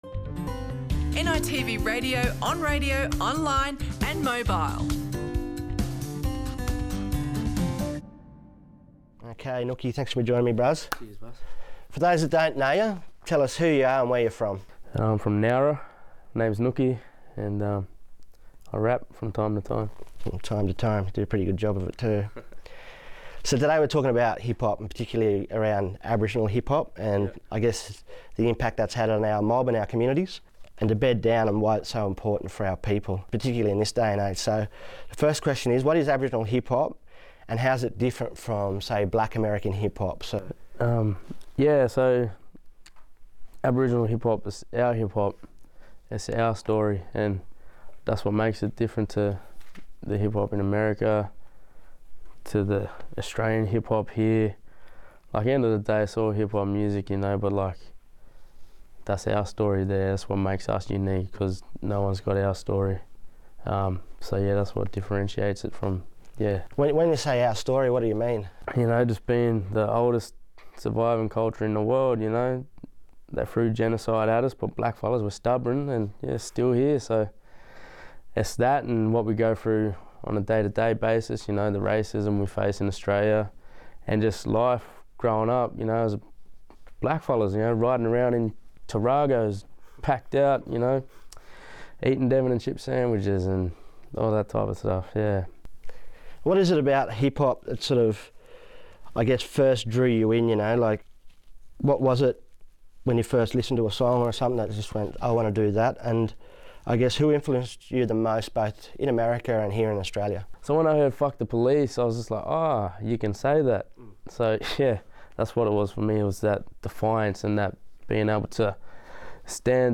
Listen to this NITV Radio podcast for the full conversation.